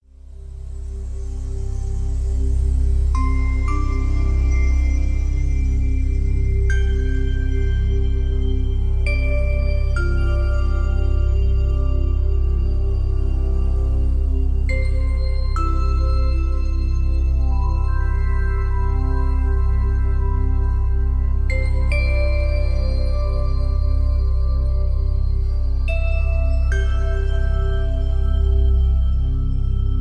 Deep Relaxtion